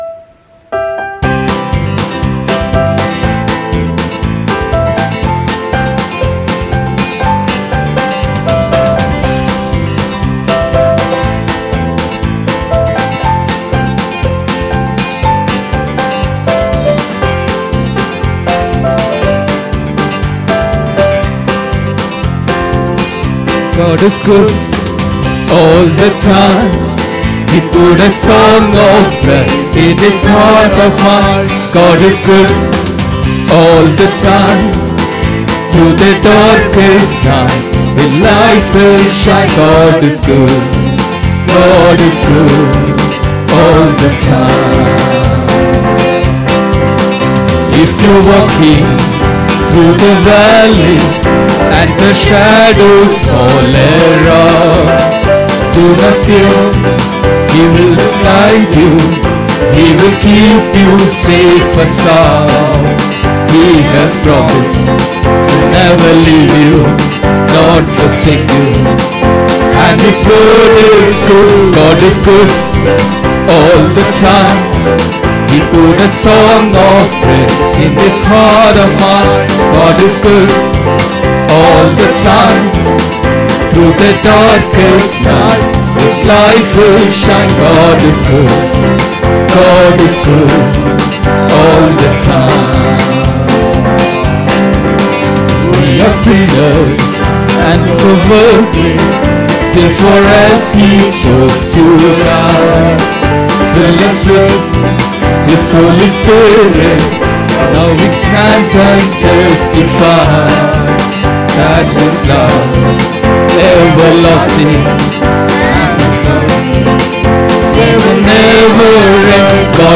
17 Sep 2023 Sunday Morning Service – Christ King Faith Mission